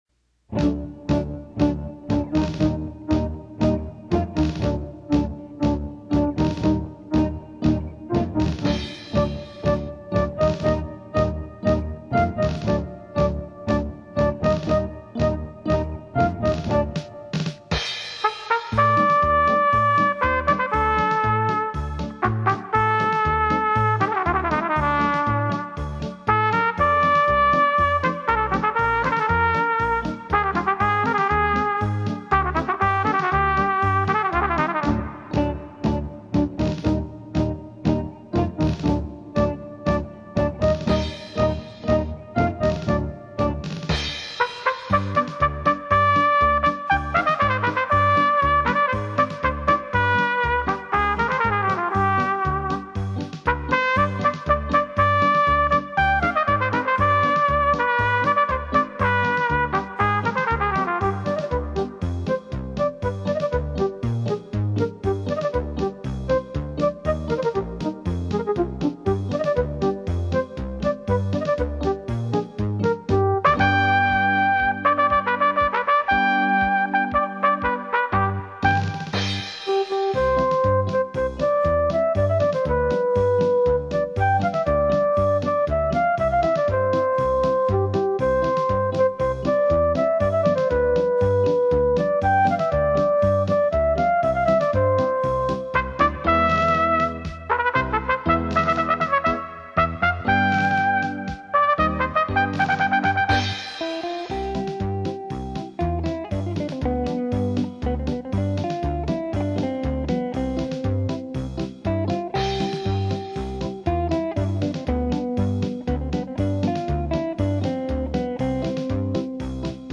Latin American, Easy Listening
pasodoble
Instrumental music to enjoy these styles of Latin dance.